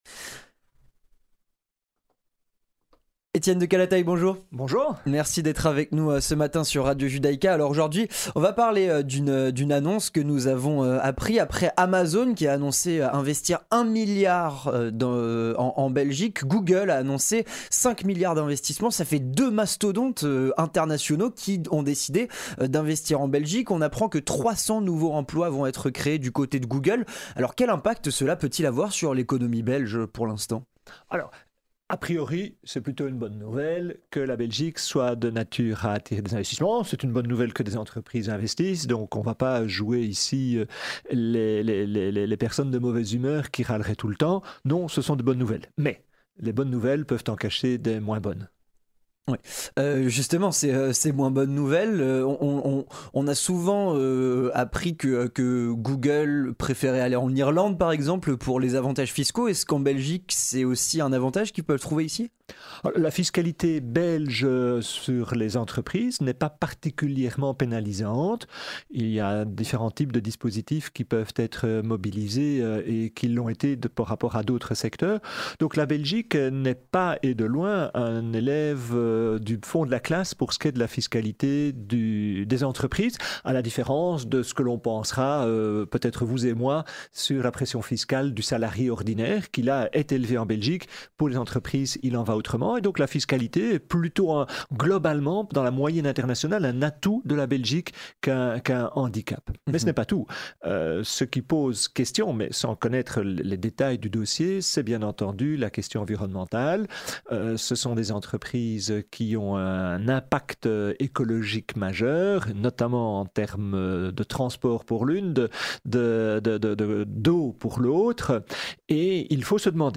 économiste